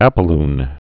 (ăpə-ln)